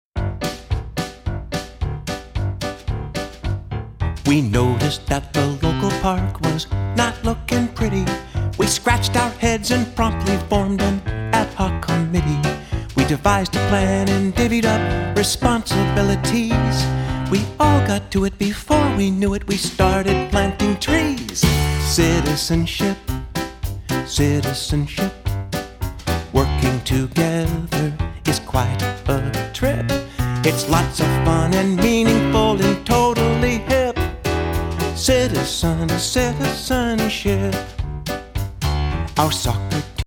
Upbeat songs that teach, not preach!